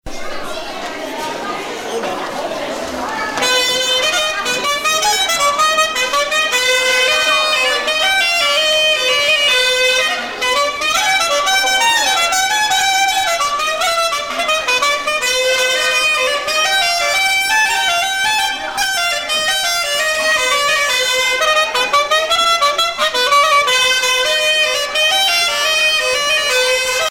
danse : fisel (bretagne)
Sonneurs de clarinette en Bretagne